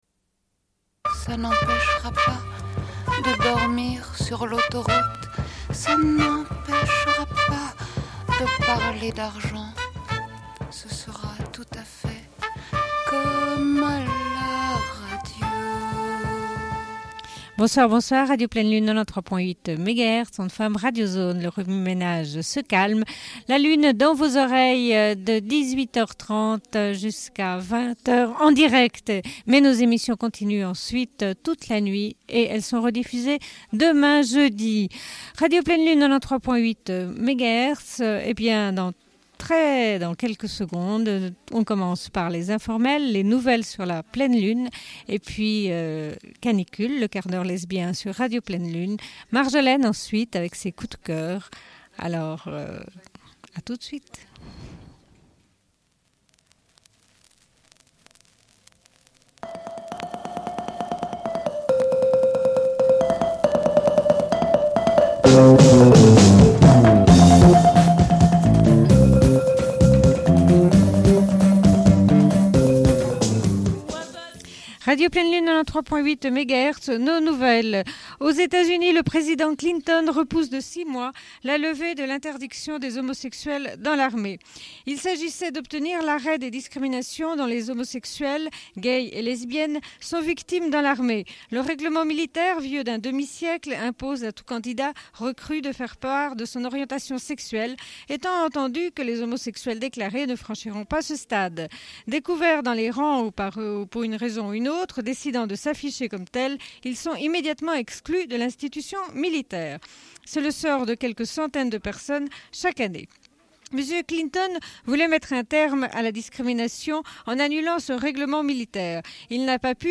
Bulletin d'information de Radio Pleine Lune du 03.02.1993 - Archives contestataires
Une cassette audio, face B29:13